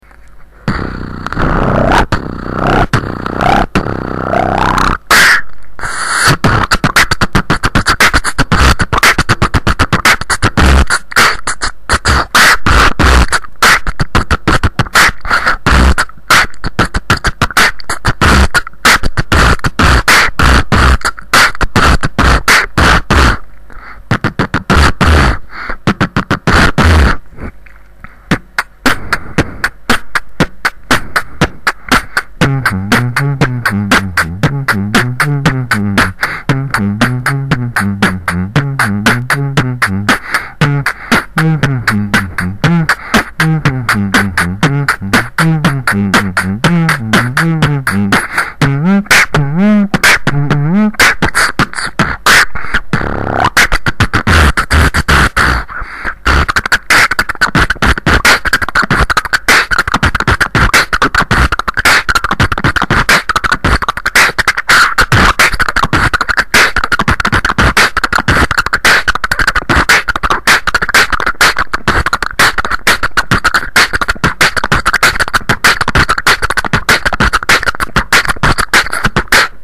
Форум российского битбокс портала » Реорганизация форума - РЕСТАВРАЦИЯ » Выкладываем видео / аудио с битбоксом » Оцените!
Делаю всего лишь 5 месяцев!Оцените пожалуйста!и извините за плохую запись!! biggrin
бит хороший